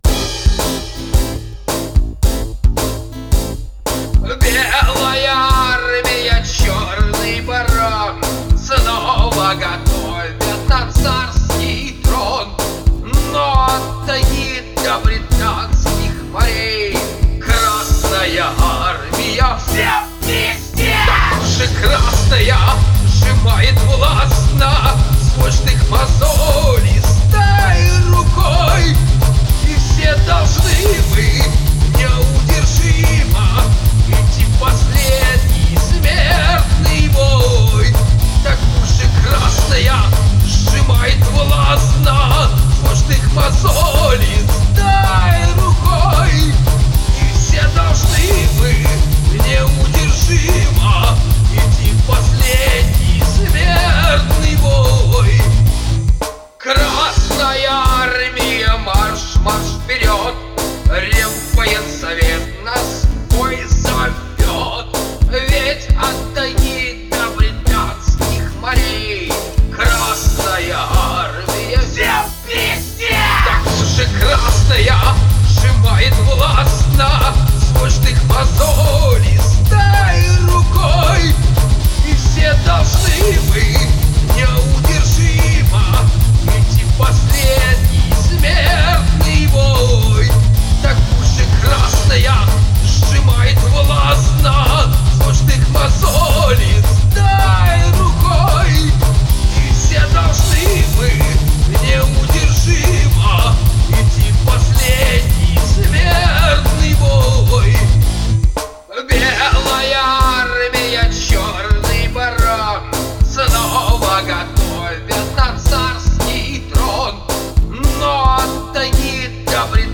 Музыкальный хостинг: /Панк